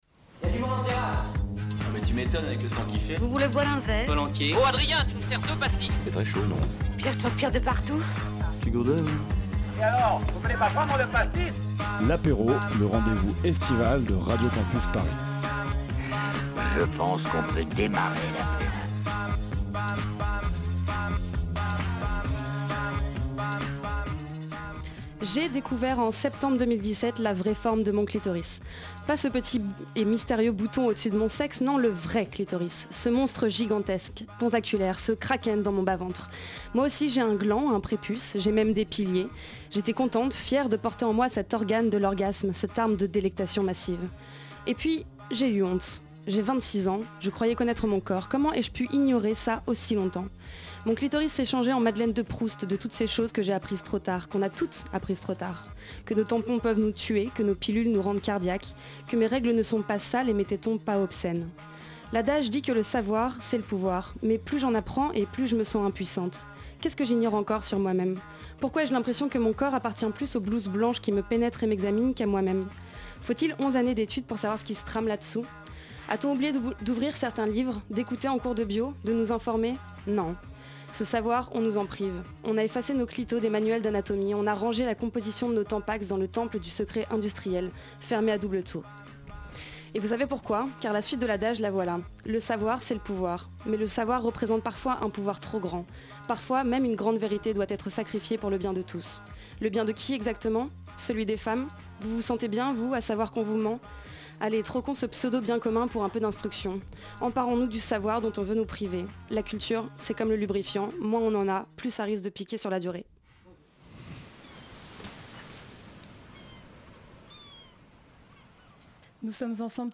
Et pour ce mois de juillet un peu spécial sur les ondes de Radio Campus Paris, les femmes s'emparent du mic.